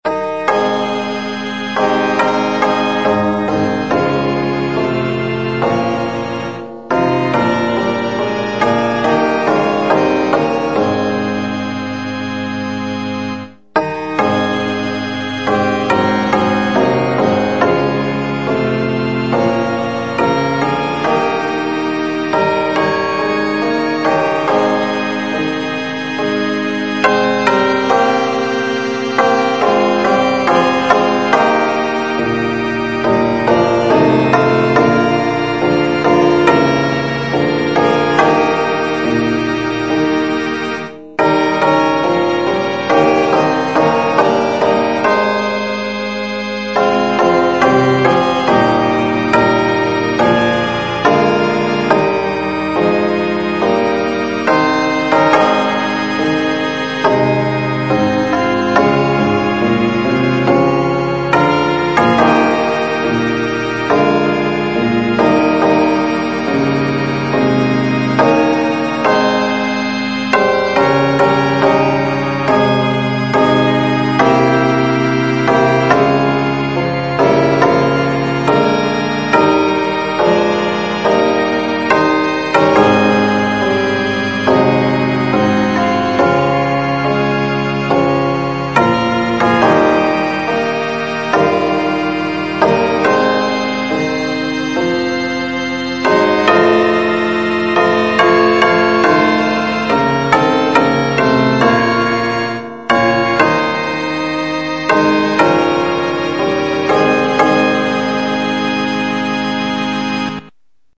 Этот минус можно слушать,но не петь и он тоже без вступления.